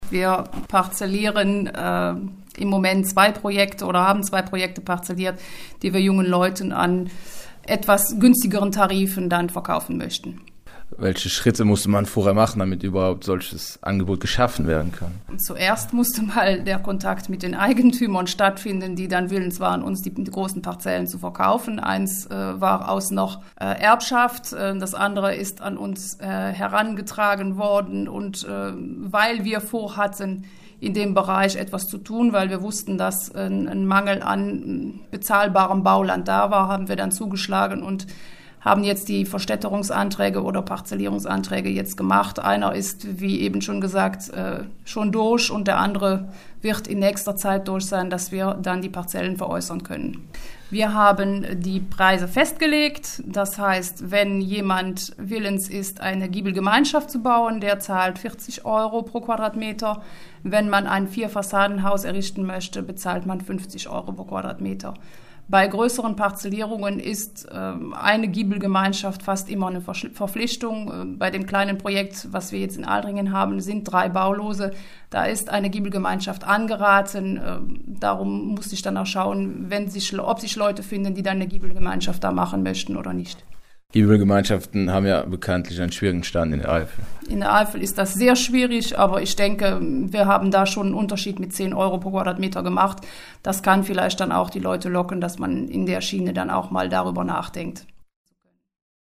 Soweit Reulands Bürgermeisterin Marion Dhur.